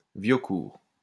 Viocourt (French pronunciation: [vjɔkuʁ]